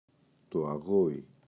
αγώι, το [aꞋγoi]